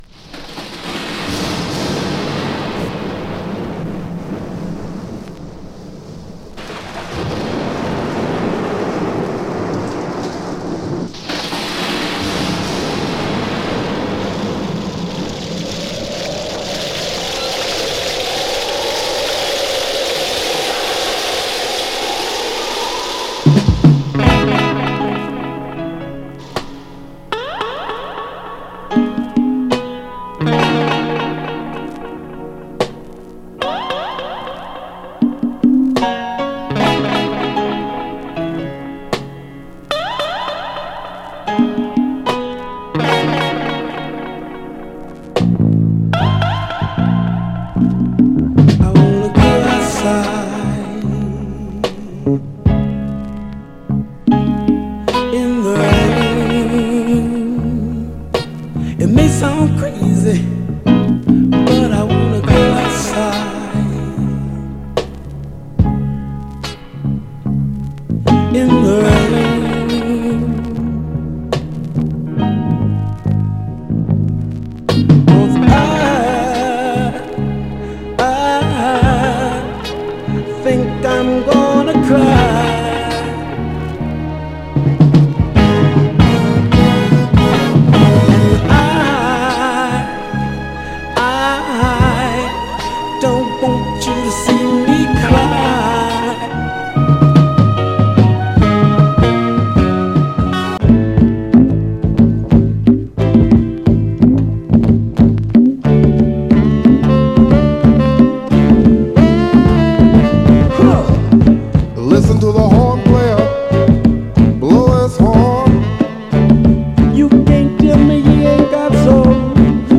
大げさなリバーブと雷雨音が効いた泣きギターのイントロから、グイグイ引き込まれる名曲ですね。
※試聴音源は実際にお送りする商品から録音したものです※